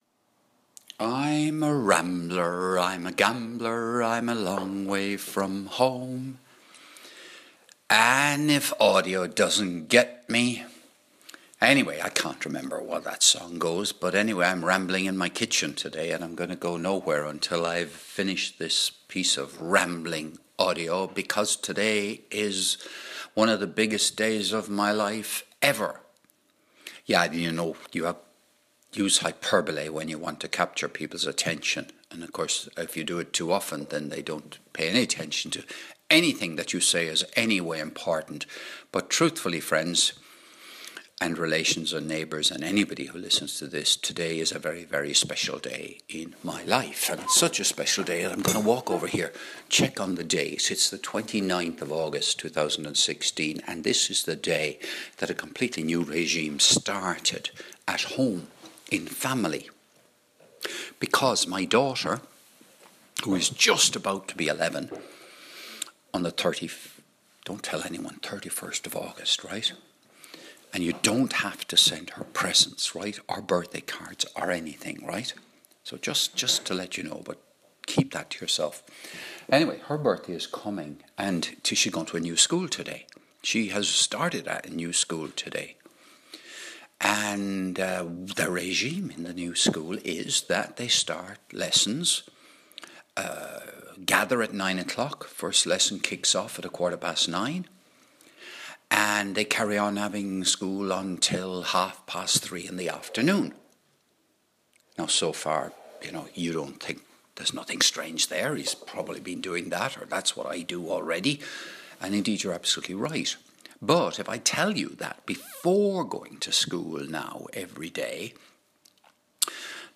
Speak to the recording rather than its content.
A Kitchen Ramble From Cork Ireland